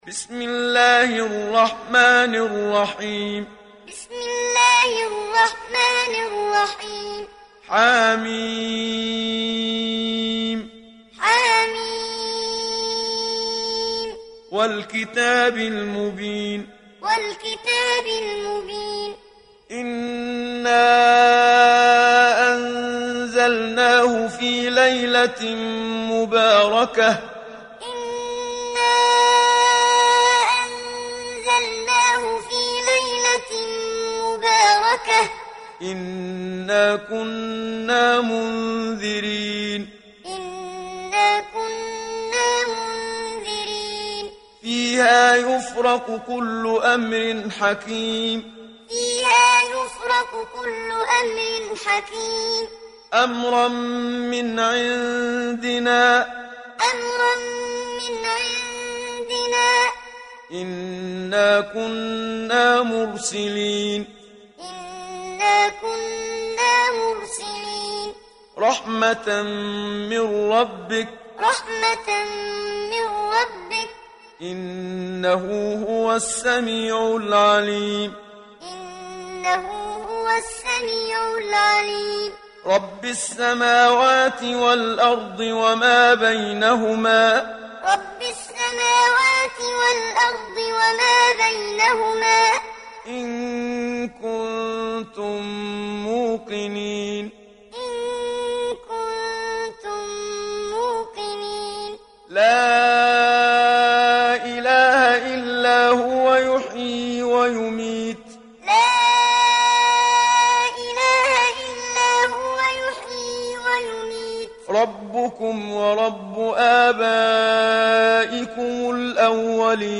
Download Surat Ad Dukhan Muhammad Siddiq Minshawi Muallim